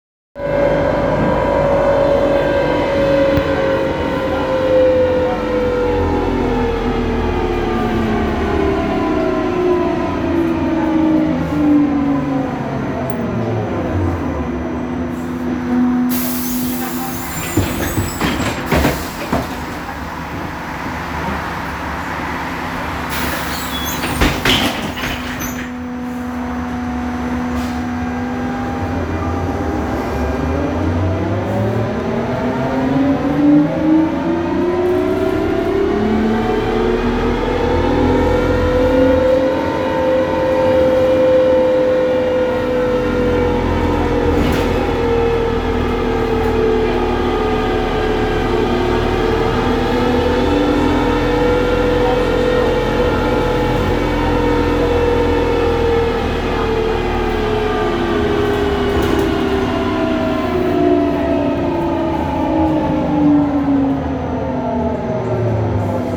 De aproape 3 luni face acel zgomot puternic.